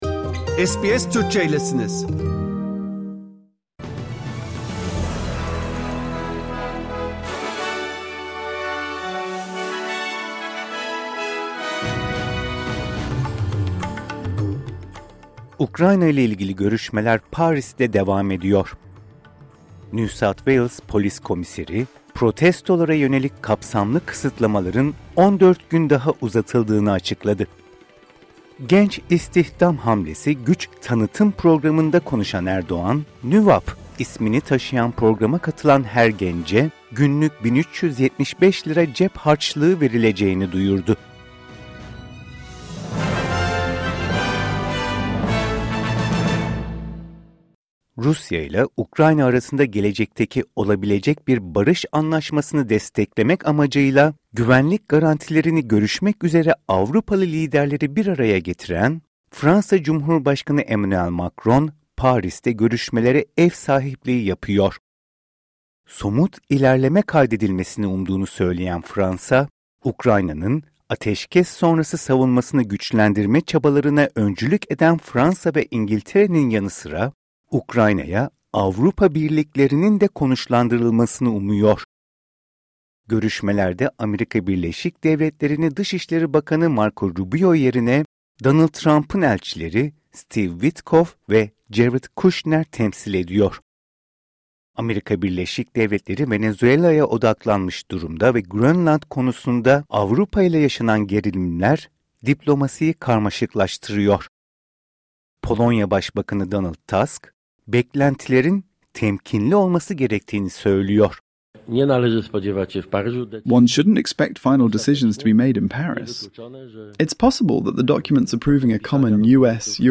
SBS Türkçe Haberler Source: SBS